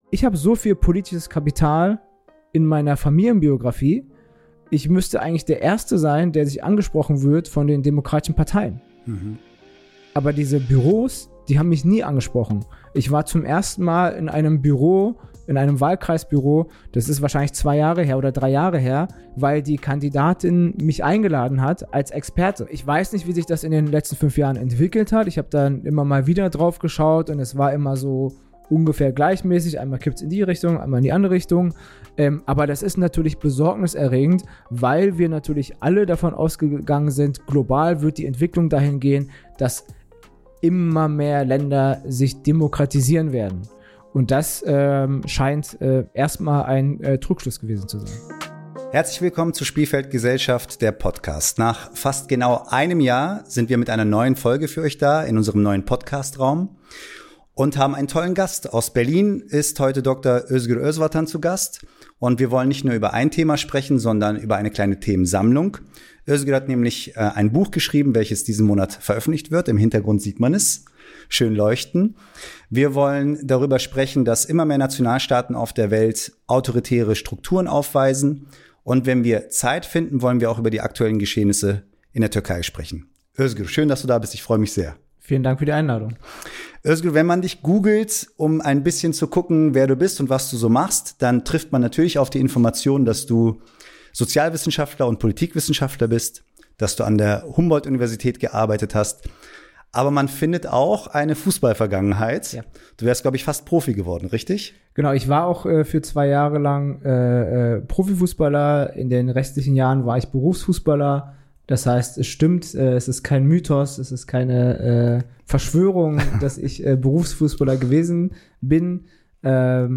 Besonders im Blick: Die aktuelle Lage in der Türkei und die Inhaftierung von Ekrem İmamoğlu. Ein Gespräch zwischen Freundschaft und politischer Analyse – hörenswert und hochaktuell.